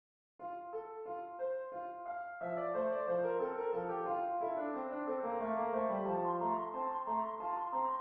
It?s not possible to rewrite all compositions this way- for example ,Bach's writing is polyphonic (it means that there is more than one melody which runs at the same time), so the left hand is playing the melody as well, and could not be represented by a single chord at that time: